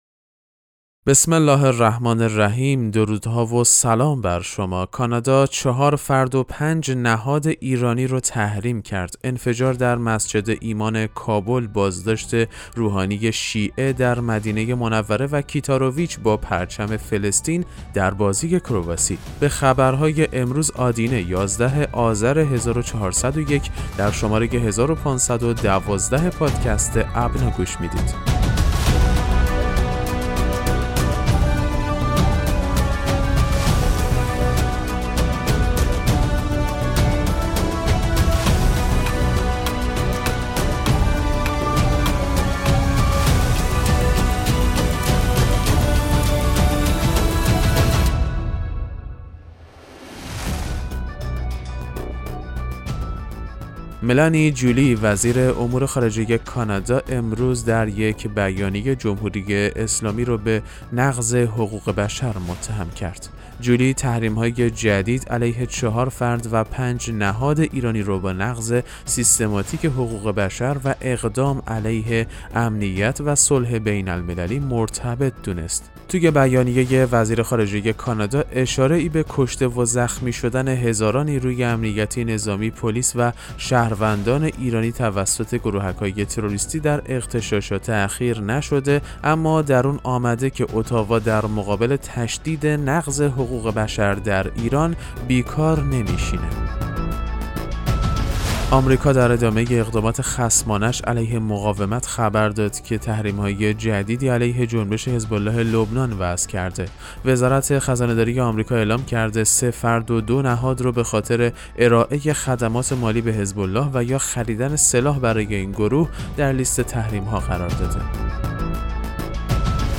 پادکست مهم‌ترین اخبار ابنا فارسی ــ 11 آذر 1401